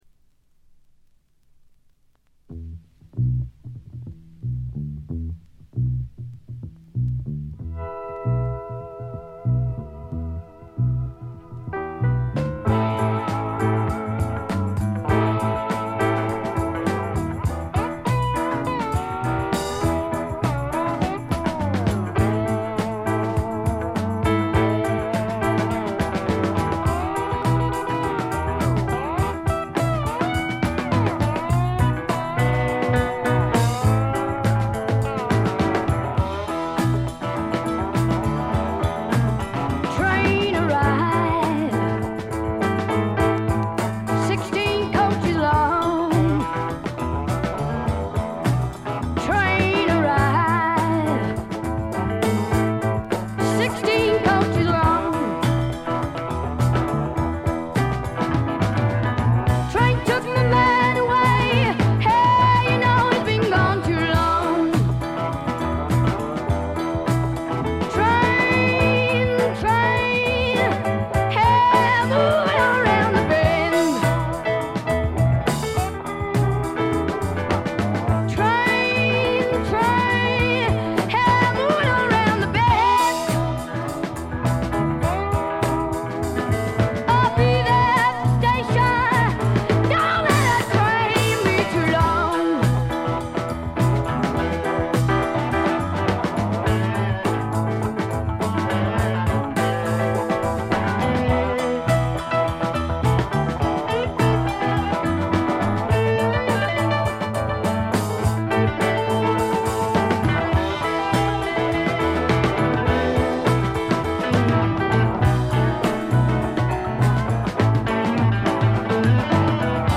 部分試聴ですが軽微なチリプチと散発的なプツ音が少し出る程度。
試聴曲は現品からの取り込み音源です。